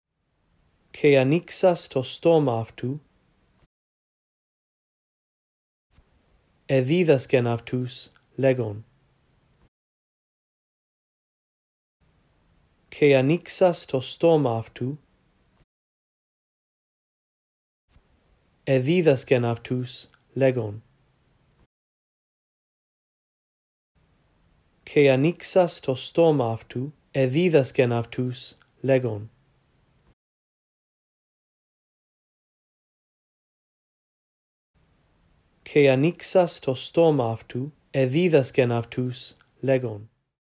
In this audio track, I read through verse 2 a phrase at a time, giving you time to repeat after me. After two run-throughs, the phrases that you are to repeat become longer.